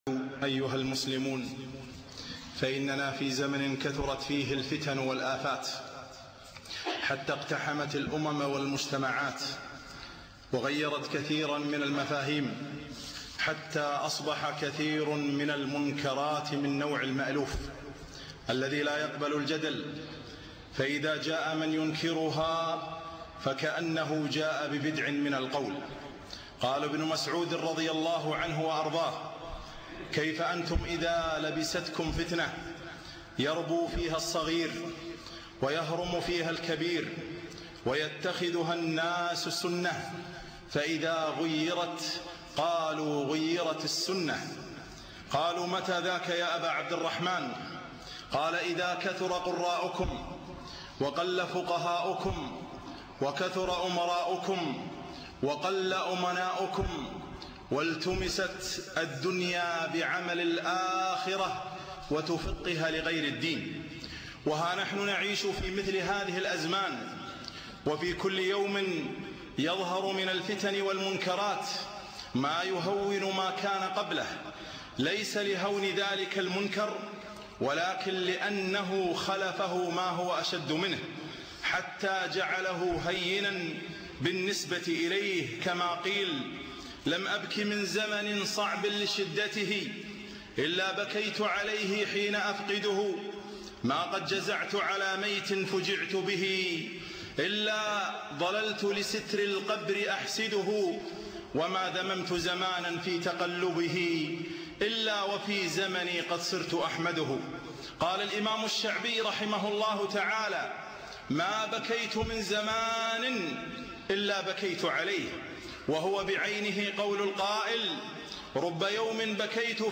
خطبة قيمة - حكم الاحتفال بعيد الحب